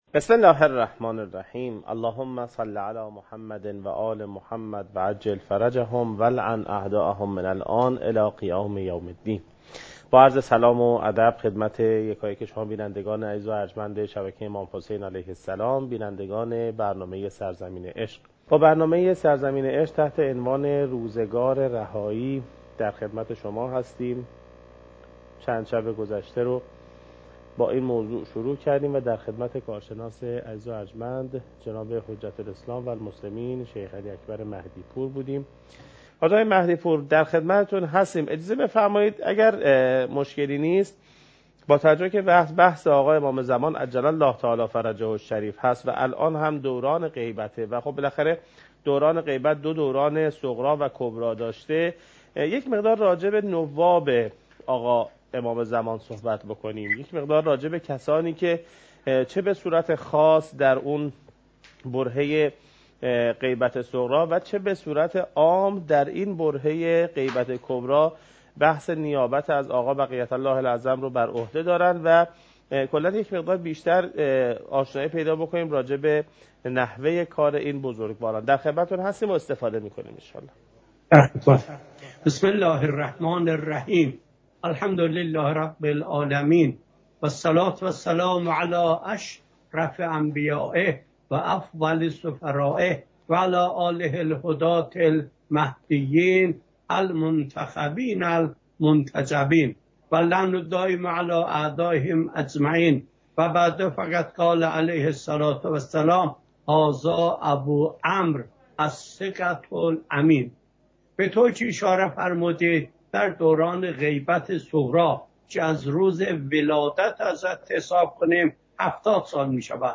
حجم: 10.1 MB | زمان: 43:07 | تاریخ: 1441هـ.ق | مکان: کربلا